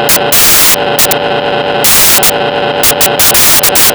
The ADSMK2 is an auto diesel train sound board that has been designed to mimic diesel engines it is a non dcc circuit board and has the advantage of being able to be adjusted to sound like different engines with just one adjustment. you can make it sound from a whining supercharged diesel to a ruff old growler.
IDLE SOUND
DIESELWHINE.wav